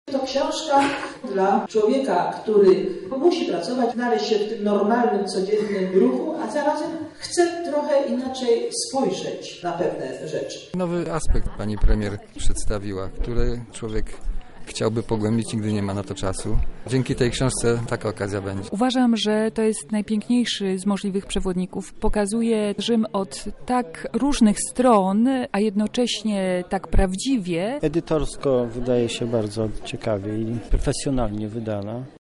Wczoraj odbyło się spotkanie i dyskusja wokół ostatniej publikacji byłej premier
„Chciałabym, żeby to co napisałam trafiło do zwykłego czytelnika”-  mówi autorka: